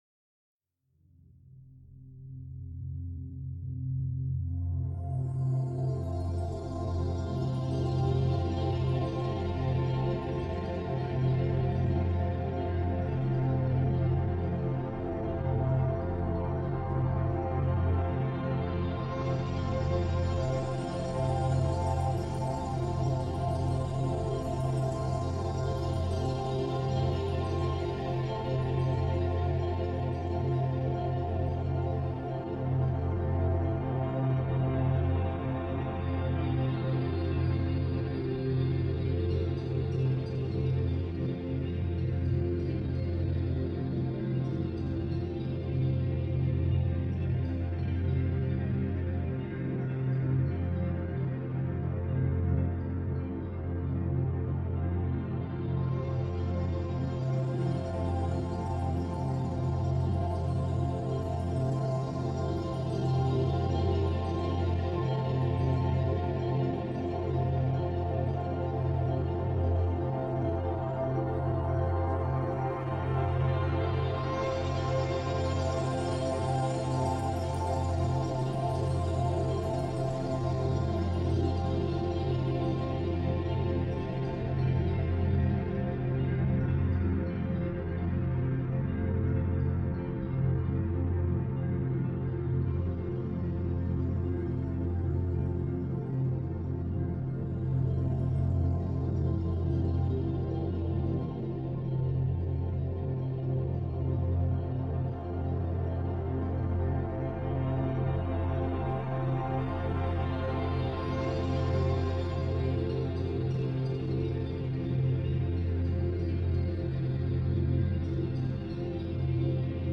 ژانر: چاکرا